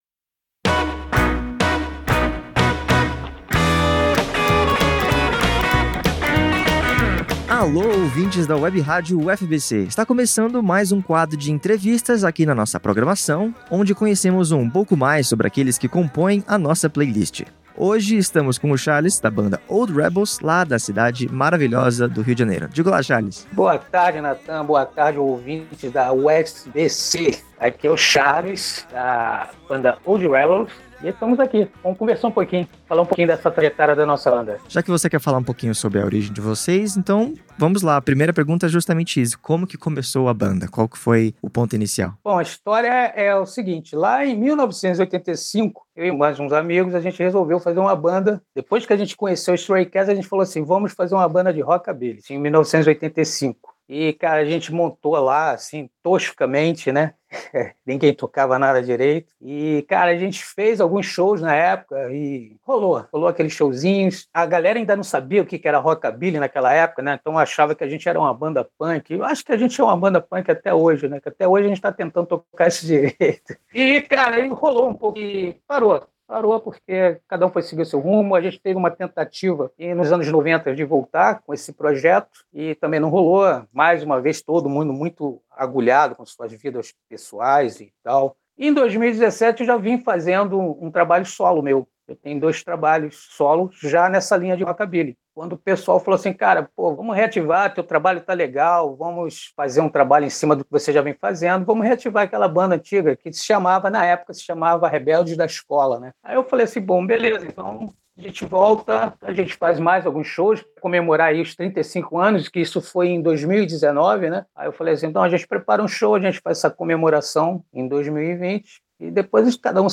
Confira a entrevista exclusiva da WebRádio UFABC com a Old Rebels Band!